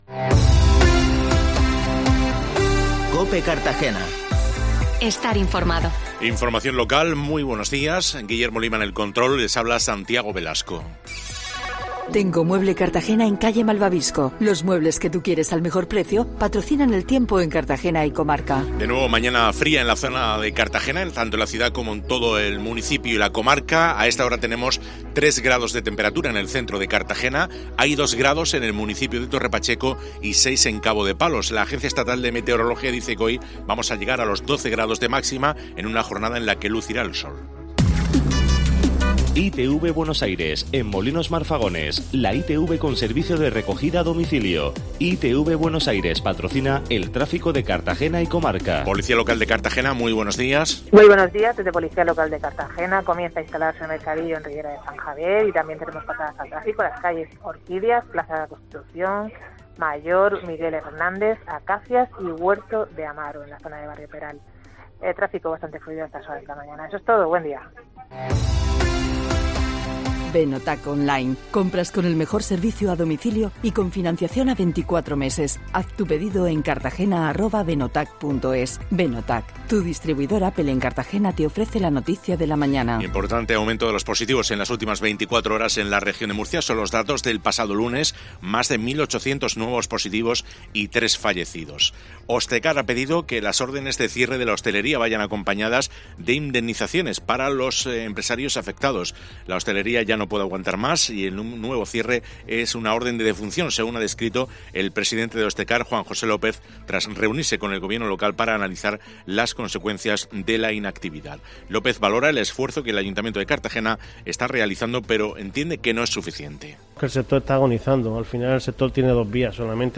Informativo Matinal COPE Cartagena 13-01